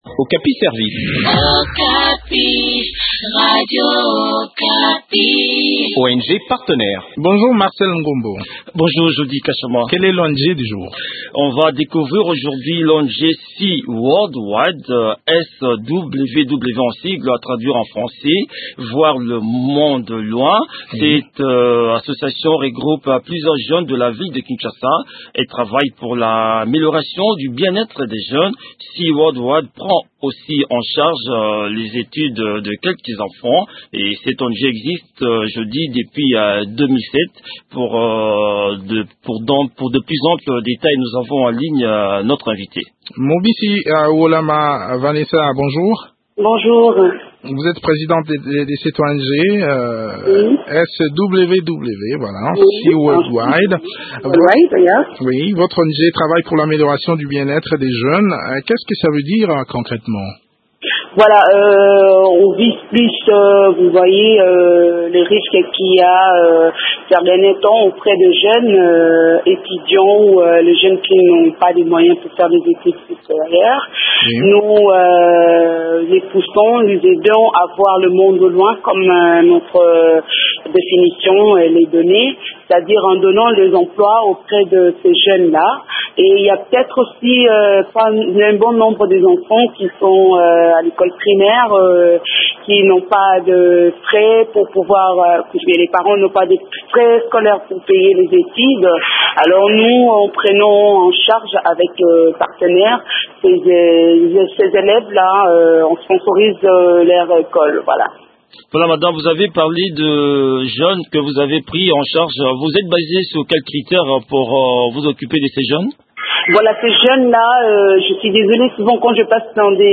Le point sur les activités de cette structure dans cet entretien